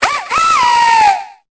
Cri d'Efflèche dans Pokémon Épée et Bouclier.